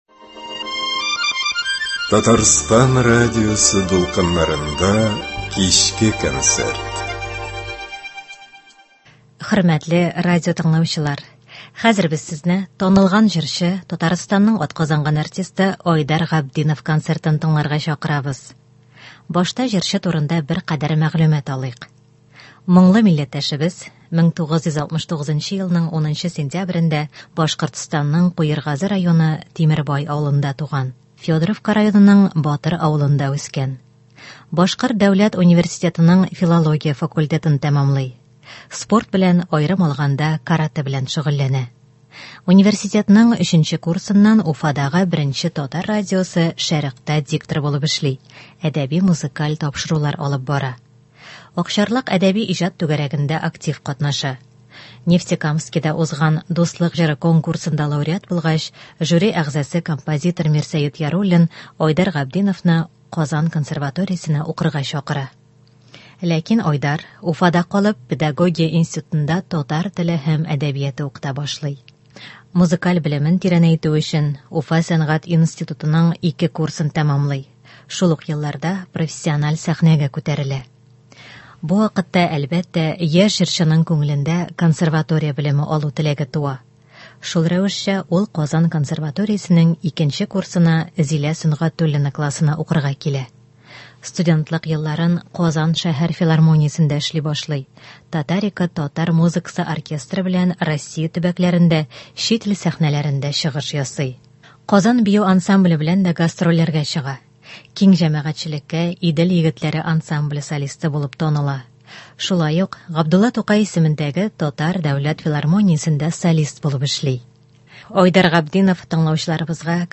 Бүгенге концерт программасын без аның башкаруындагы халык җырларыннан төзедек.